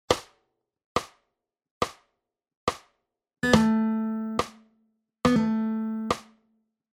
Короткий вспомогательный звук, исполняемый непосредственно перед основным.
В первом случае основная нота звучит точно на долю (на удар метронома), а вспомогательная нота исполняется немного раньше, «забирая» часть времени у предыдущего такта.
Во втором варианте, наоборот, маленькая нота форшлага приходится на долю, а основная нота извлекается чуть позже.
Исполнение: форшлаг исполняется быстро, без нарушения пульса.
forshlagi-zvuk.mp3